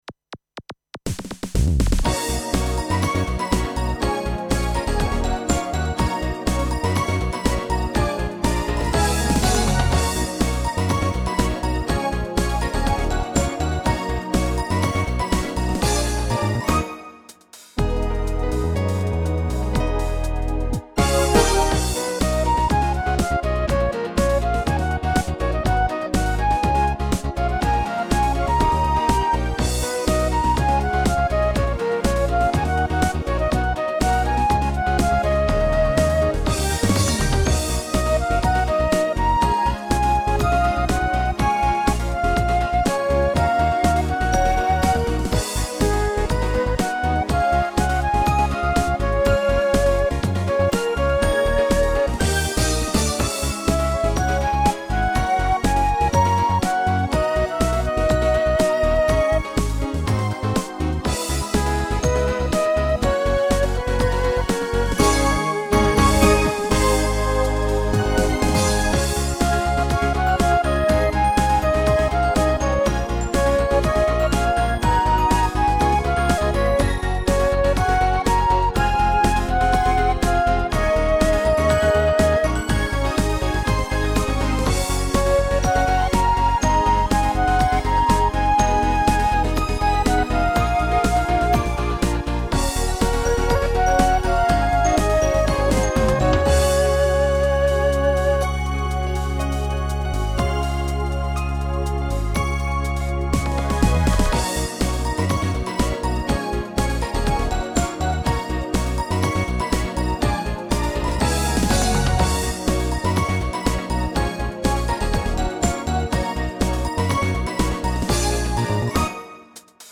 SC-88Pro用、オリジナル:MU100用